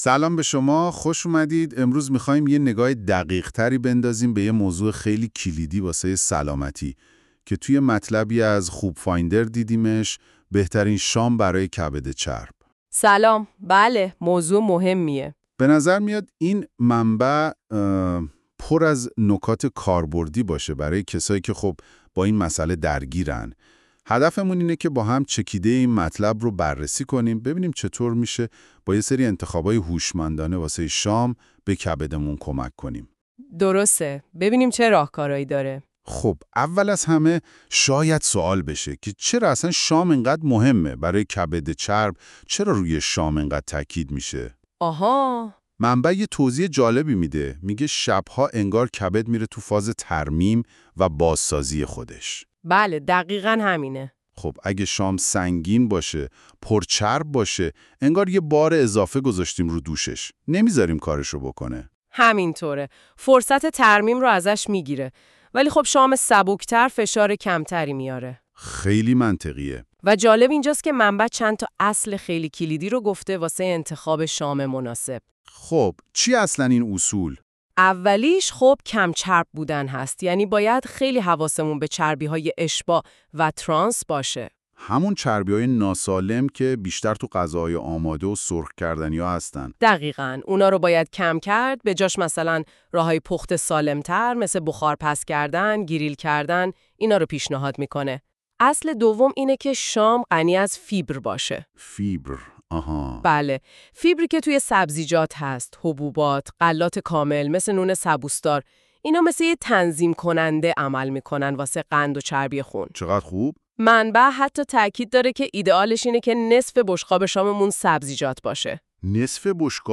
این خلاصه صوتی به صورت پادکست و توسط هوش مصنوعی تولید شده است.